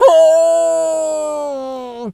wolf_hurt_05.wav